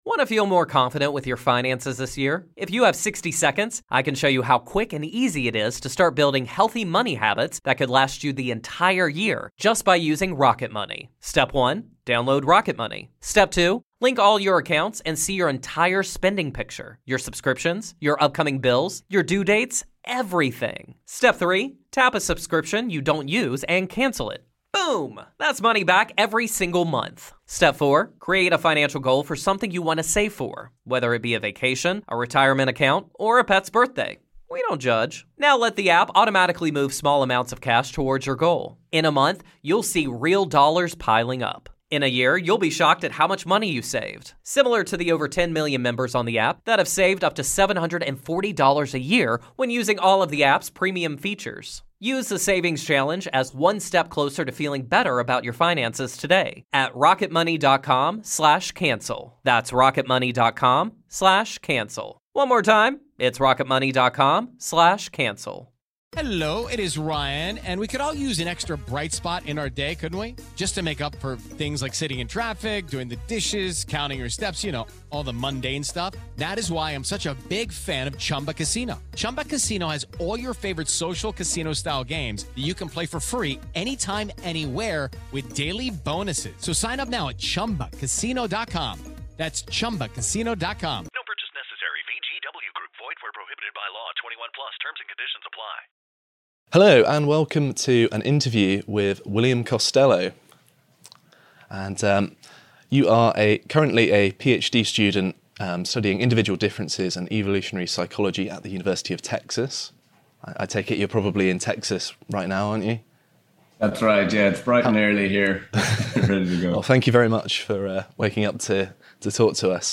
VIDEO: Understanding Incels | Interview